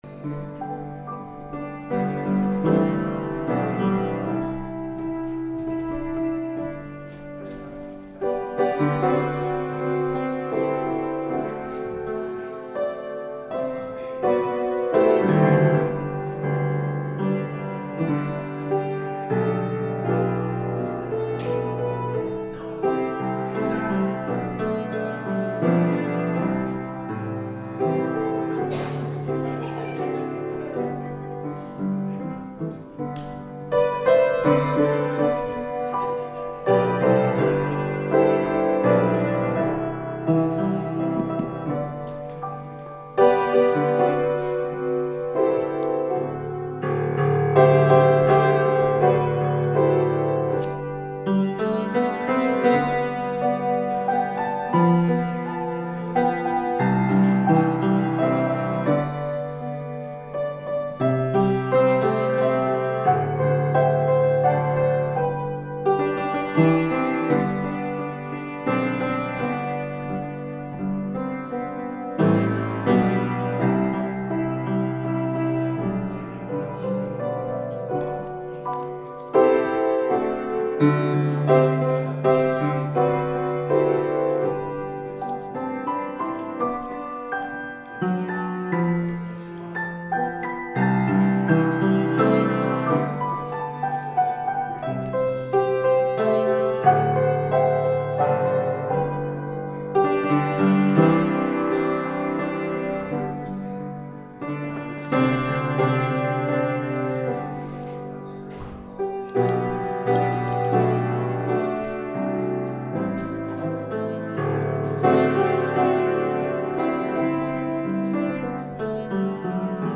The Riches of God’s People – Last Trumpet Ministries – Truth Tabernacle – Sermon Library